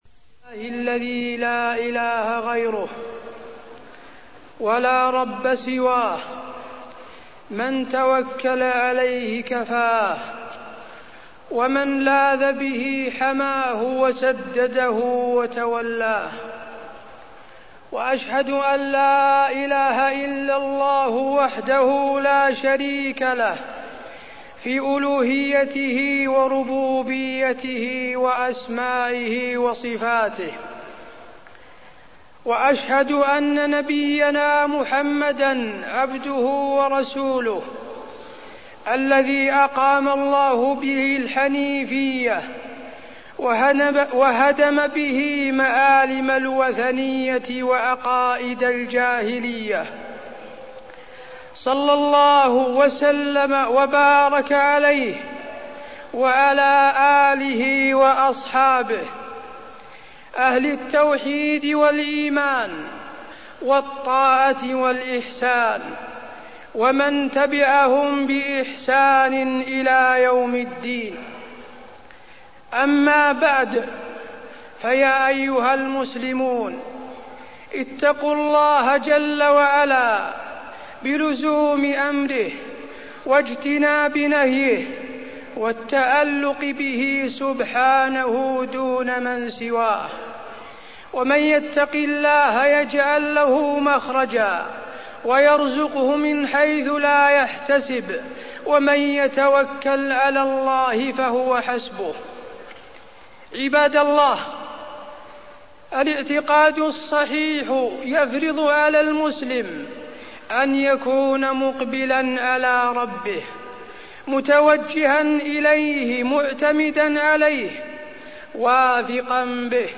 تاريخ النشر ٤ صفر ١٤١٩ هـ المكان: المسجد النبوي الشيخ: فضيلة الشيخ د. حسين بن عبدالعزيز آل الشيخ فضيلة الشيخ د. حسين بن عبدالعزيز آل الشيخ التشاؤم والطيرة